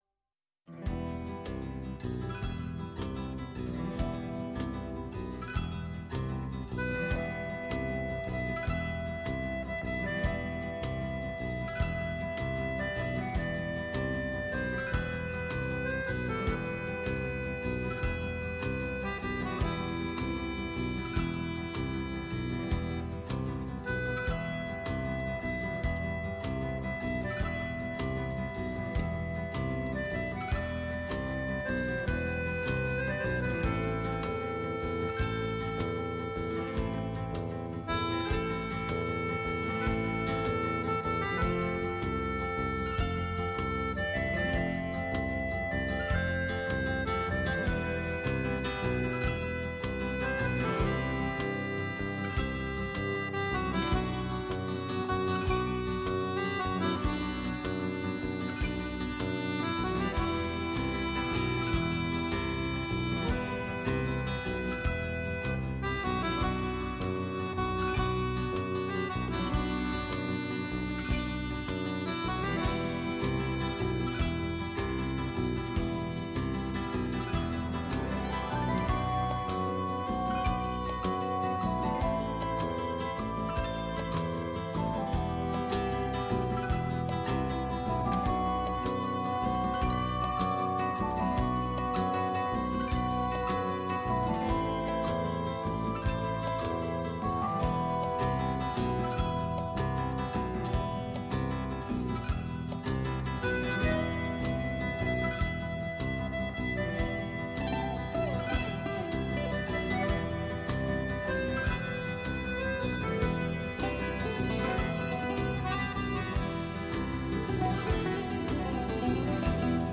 Ακούστηκαν ποιήματά του σε απαγγελίες του ίδιου και του Γιώργου Κατσίμπαλη, μελοποιημένη ποίησή του από τον Λουκά Θάνο και τον Μιχάλη Τερζή και το πως περιέγραφε την ημέρα της κηδείας του, εν μέσω Κατοχής, ο Μενέλαος Λουντέμης